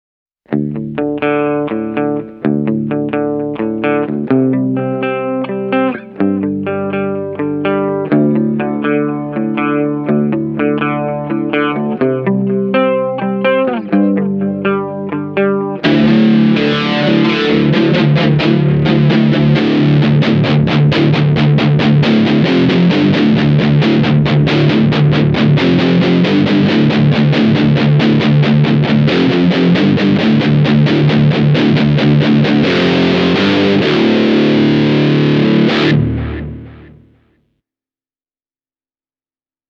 valeton-dapper-dark-e28093-basic-tones.mp3